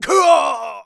dead_2.wav